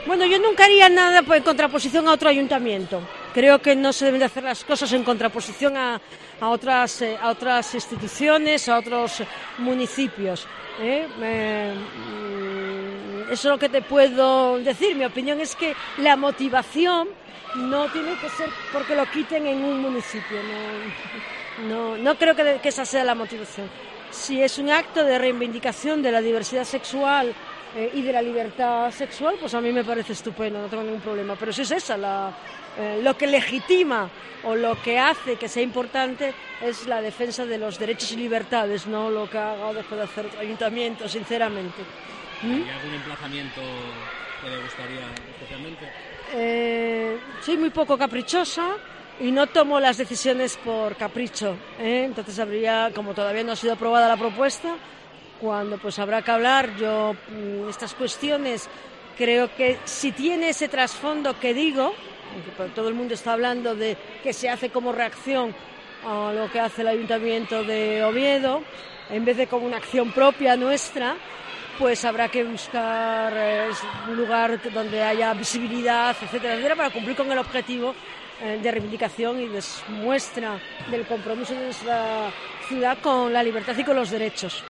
La alcaldesa de Gijón, Ana González, sobre la instalación de bancos arcoíris